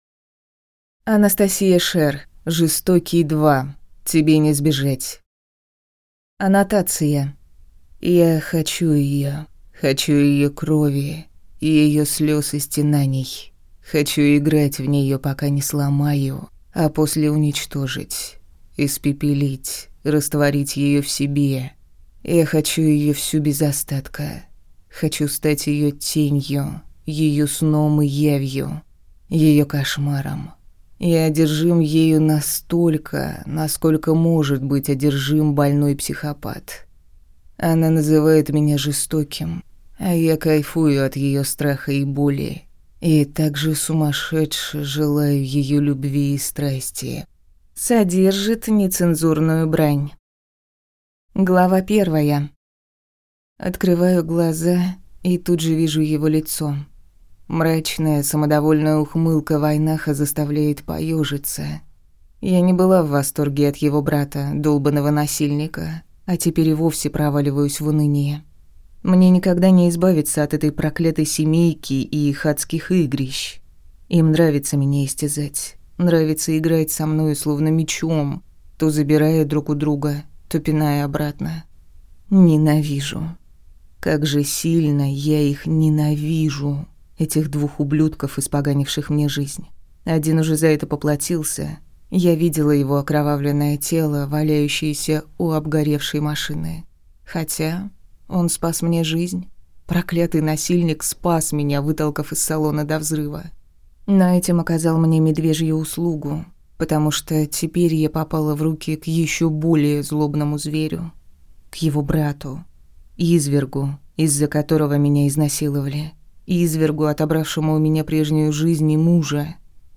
Аудиокнига Жестокий 2. Тебе не сбежать | Библиотека аудиокниг